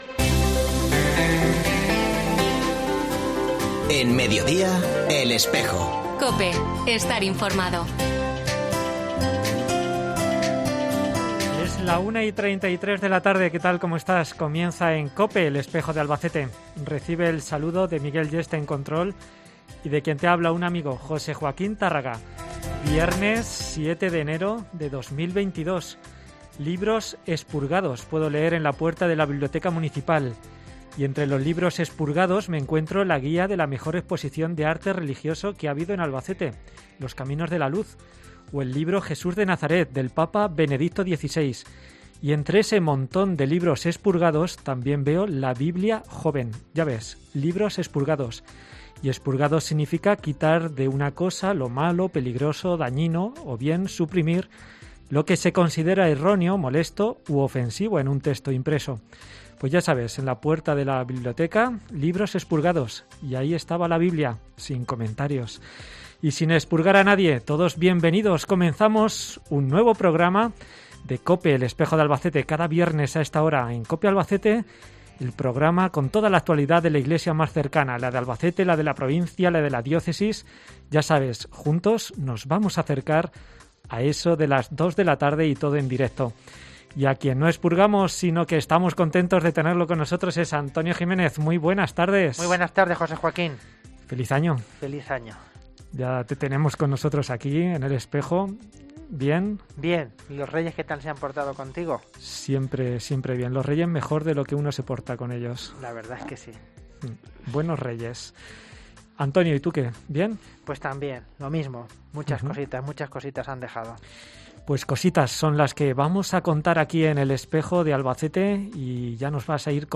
sus secciones habituales y una entrevista